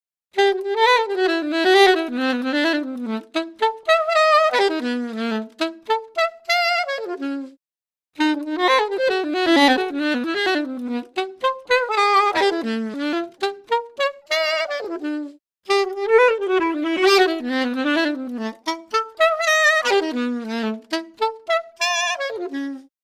die formanten schiebe ich im 3ten teil einwenig herum,was den Klang weniger „realistisch“ erscheinen lässt,aber auch möglich macht, das sax in gewisser weise noch mehr dynamik aufzuzwingen (beissender)..
saxset.mp3